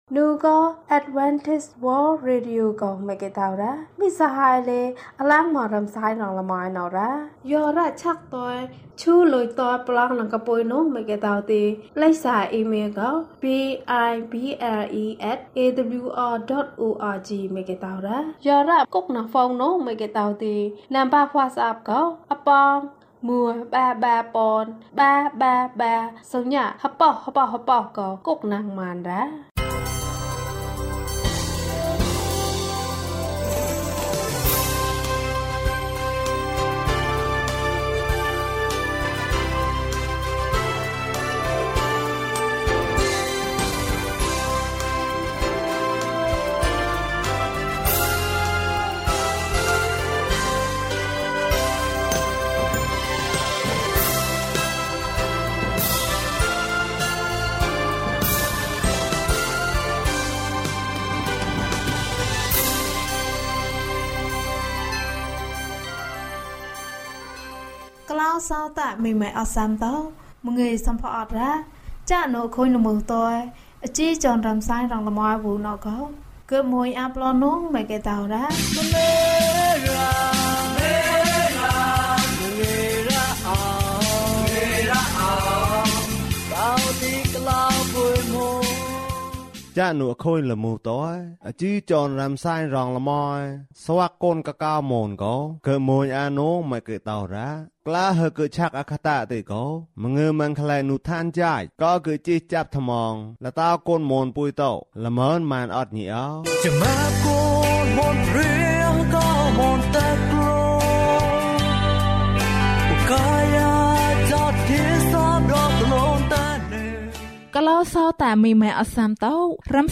Daily Radio program in Mon (ဘာသာ မန် / မွန်ဘာသာ) by AWR